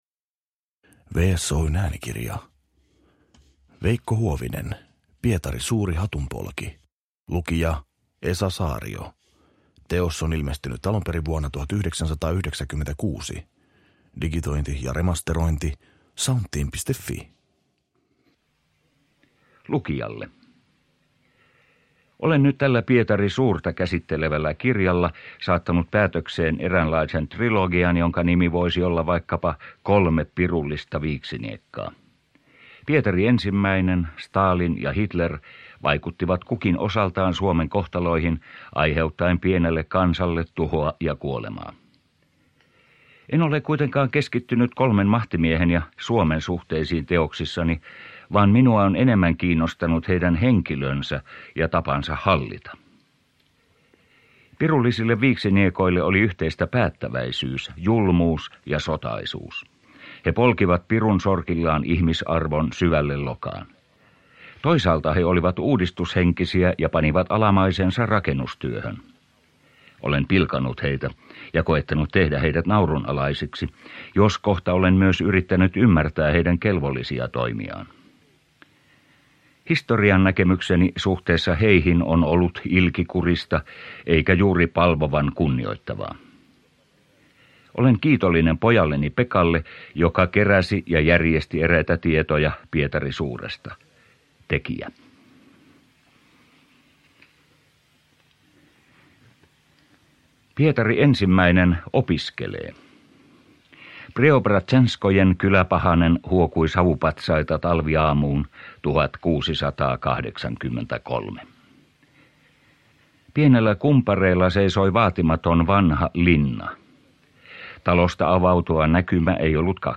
Pietari Suuri hatun polki – Ljudbok